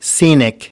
9. scenic (adj) /ˈsiːnɪk/: có cảnh đẹp thiên nhiên